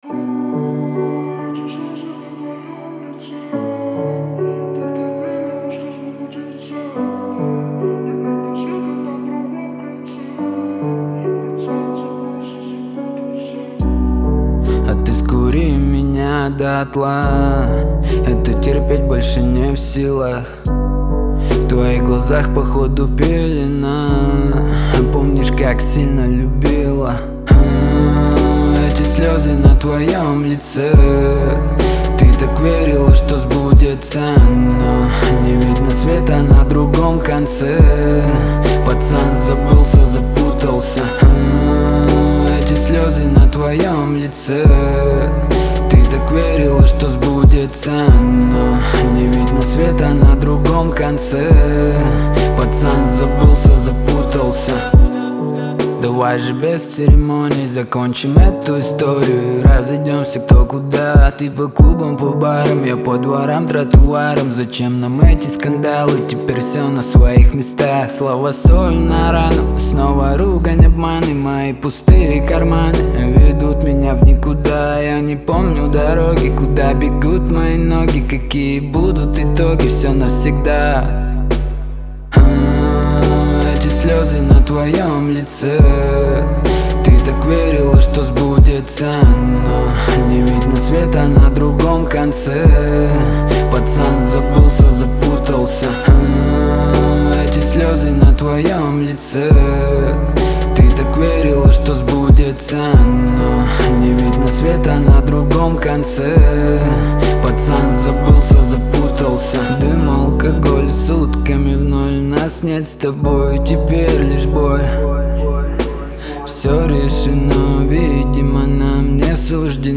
в жанре поп с элементами R&B